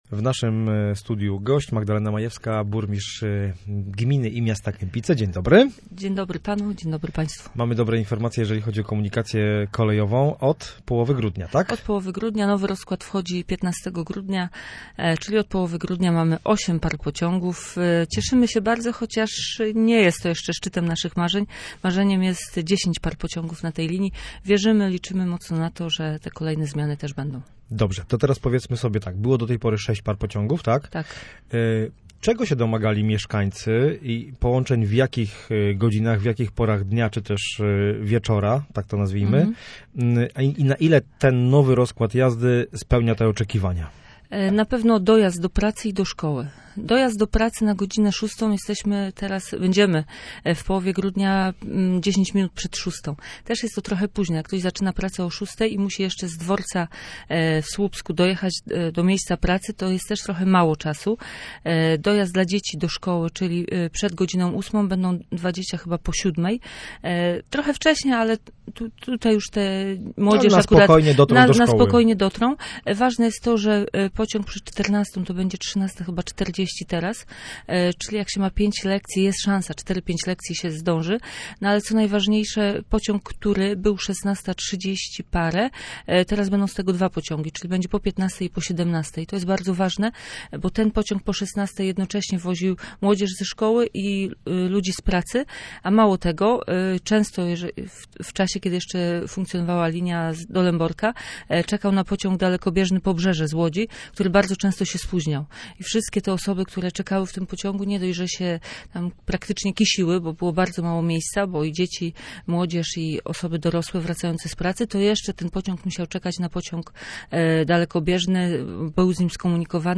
Posłuchaj rozmowy z burmistrz Kępic Magdaleną Majewską: https